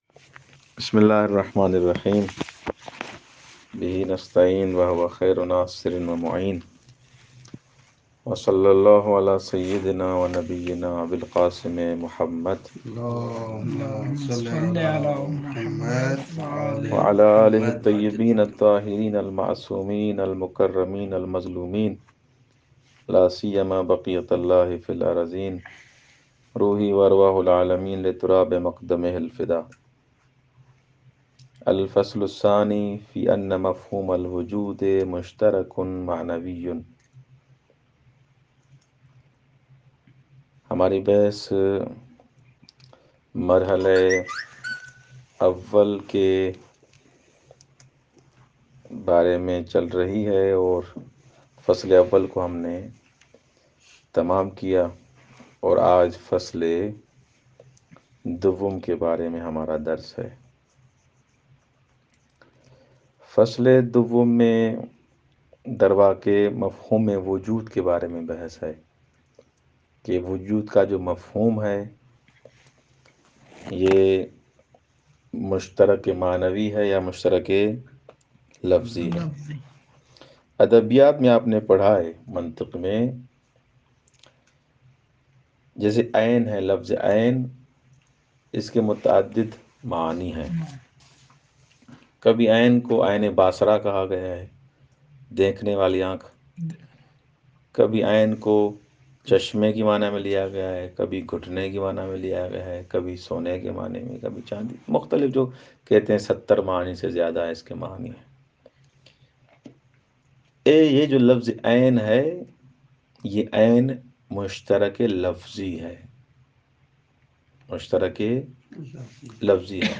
درس بدایہ 4